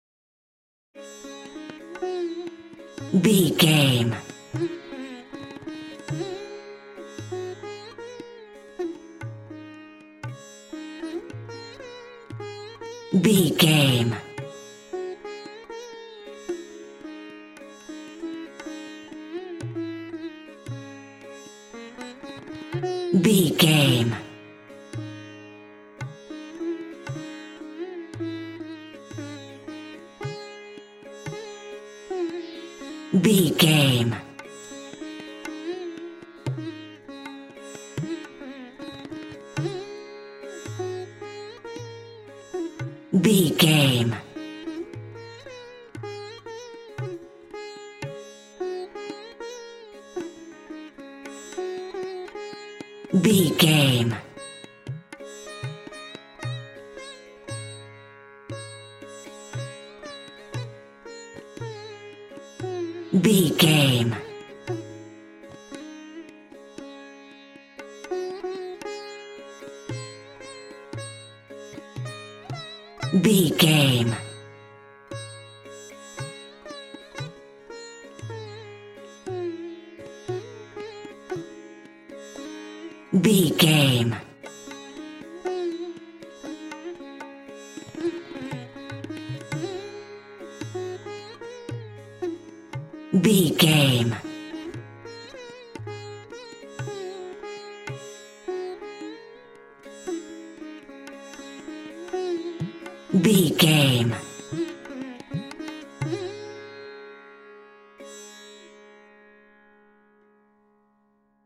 Aeolian/Minor
C#
World Music